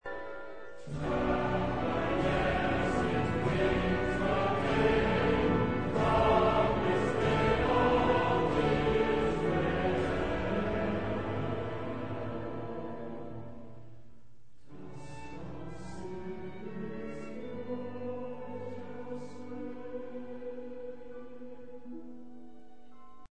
فانفارهای جدی با گروه کر فرح بخشی جواب می گیرند و نیز خاطراتی از “بیت عنیا” نوئل میسن که آیوز در کوارتت زهی شماره دو خود از آن استفاده کرده بود و “نگهبان، از شب برای ما بگو”.